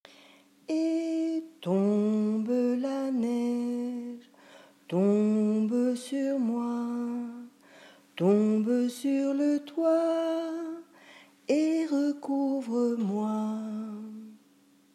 Merci de votre indulgence pour les enregistrements improvisés !